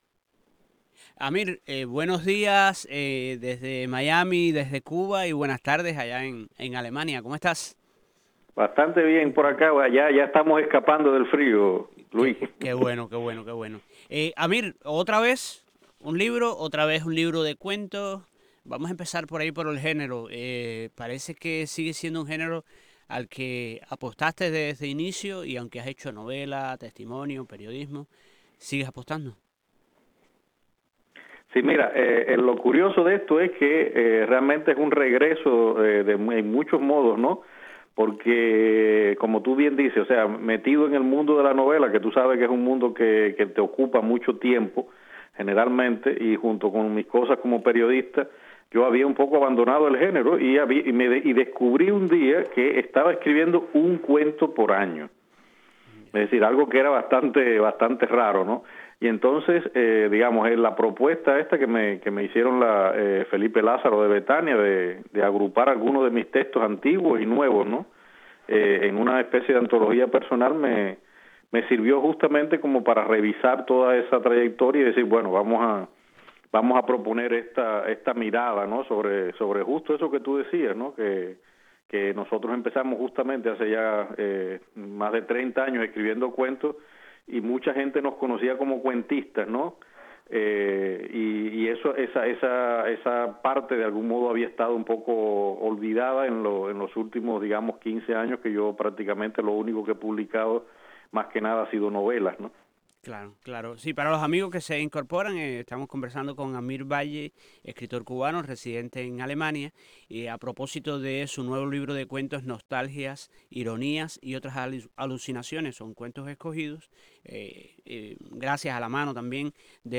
En conversación con Martí Noticias, Amir habló de los personajes marginados que pueblan sus textos, de los lectores furtivos y fieles, como aquellos que persiguieron -hasta conseguirlo- su estudio "Habana Babilonia".